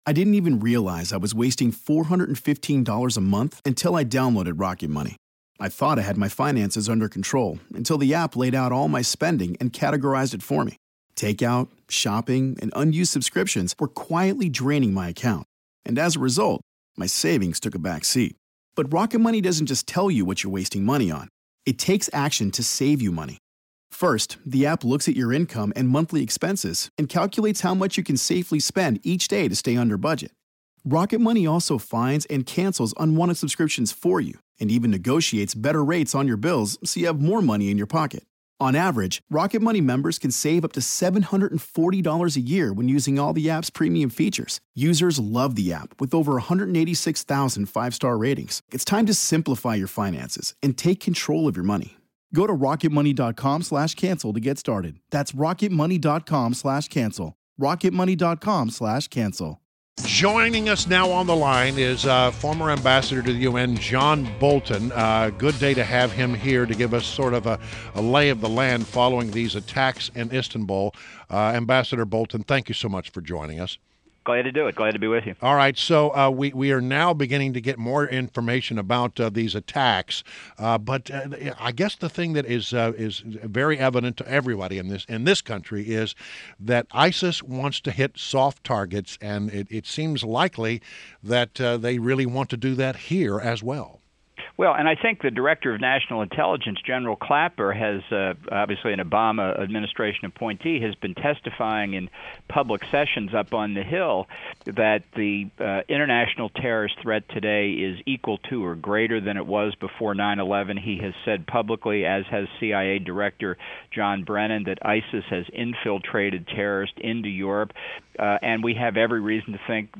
WMAL Interview - John Bolton - 06.30.16